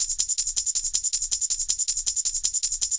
Tambo Loop B 160.wav